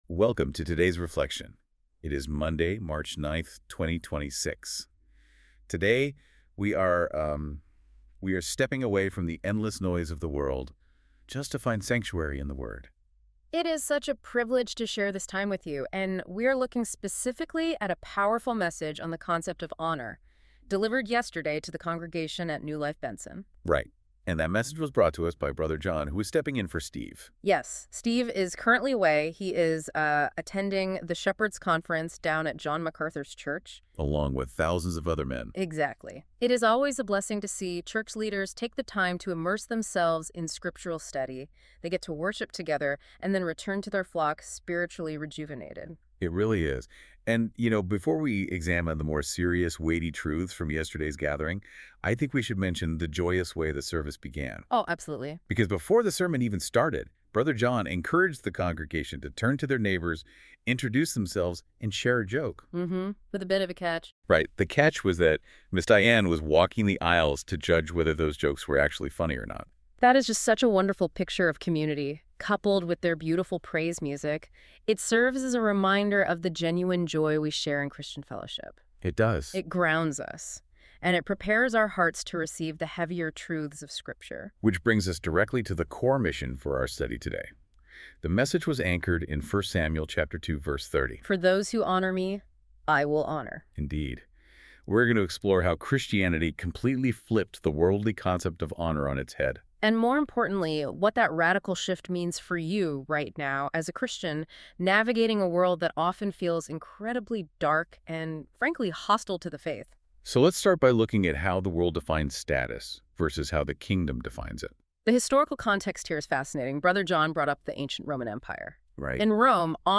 Sermons | NEW LIFE FAMILY WORSHIP CENTER